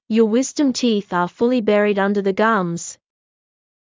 ﾕｱ ｳｨｽﾞﾀﾞﾑ ﾃｨｰｽ ｱｰ ﾌｰﾘｰ ﾌﾞﾘｨﾄﾞ ｱﾝﾀﾞｰ ｻﾞ ｶﾞﾑｽﾞ